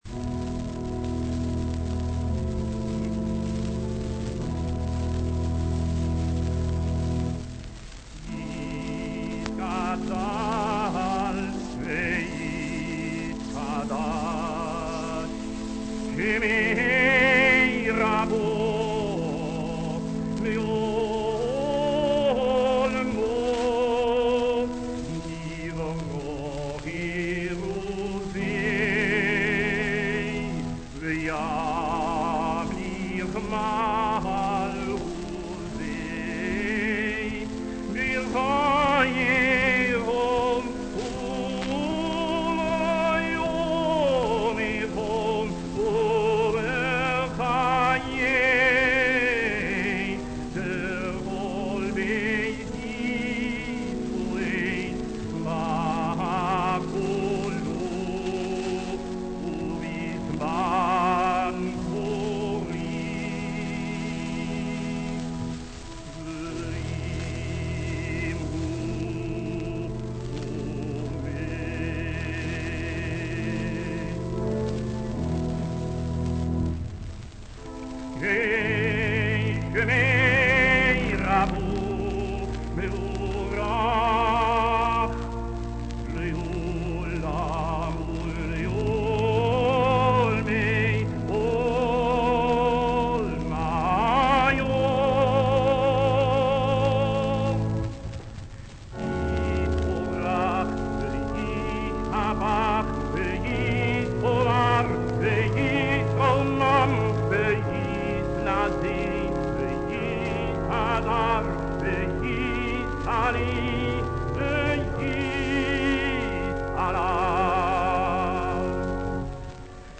Kaddisch unknown recording and singer: the only work in which Fuchs resorts to modal writing